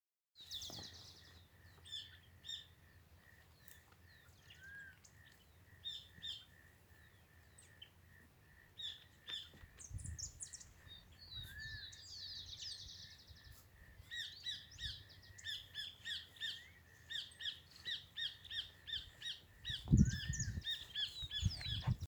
Burlisto Pico Canela (Myiarchus swainsoni)
Nombre en inglés: Swainson´s Flycatcher
Localización detallada: Estancia La Reserva
Condición: Silvestre
Certeza: Observada, Vocalización Grabada
Burlisto-pico-canela-1_1.mp3